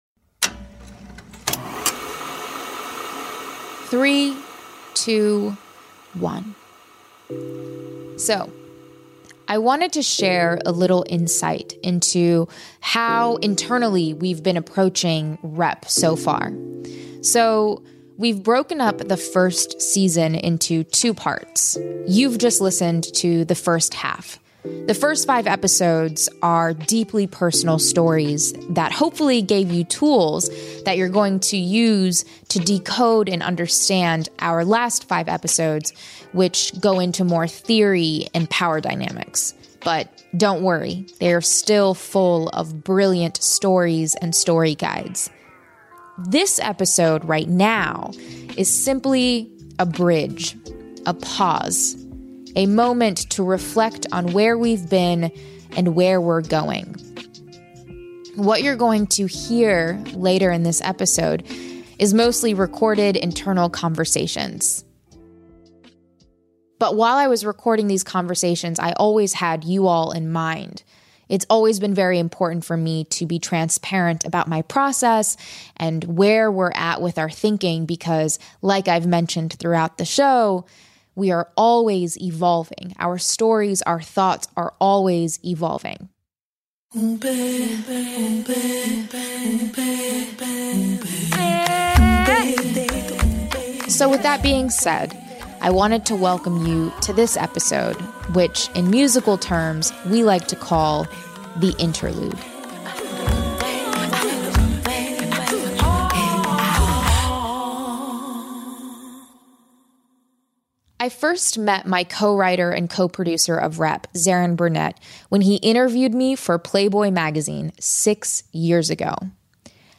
These are recorded meeting sessions reflecting on intentions.